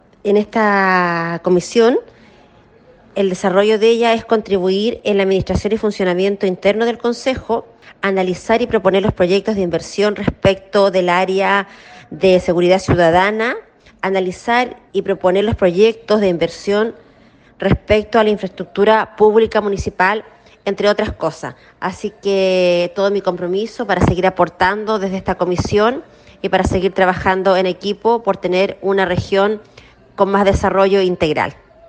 Por su parte, la consejera Paola Cortés agradeció la propuesta de acuerdo en la que presidiría la comisión de Régimen Interno, encargada de abordar temas de funcionamiento interno, además de seguridad ciudadana, bomberos, edificios consistoriales, entre otros.